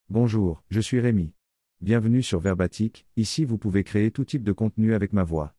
Rémi — Male French AI voice
Rémi is a male AI voice for French.
Voice sample
Listen to Rémi's male French voice.
Rémi delivers clear pronunciation with authentic French intonation, making your content sound professionally produced.